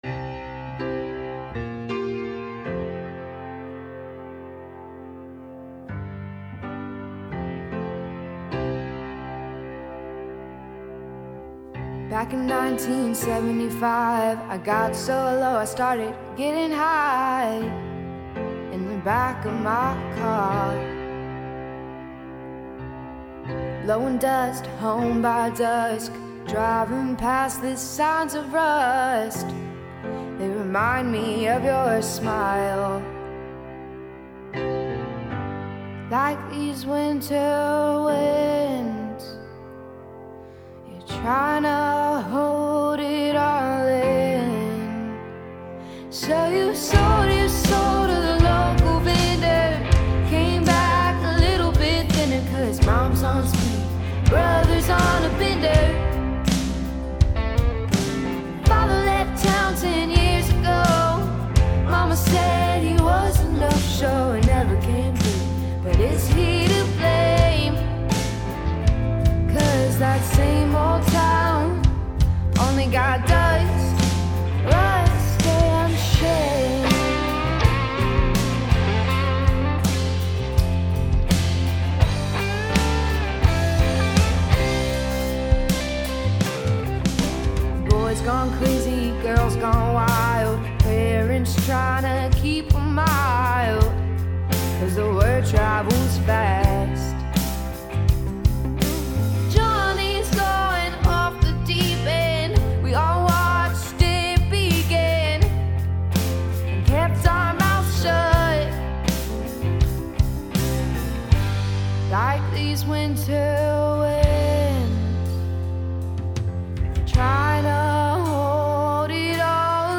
Drums, Guitar, Bass and background vocals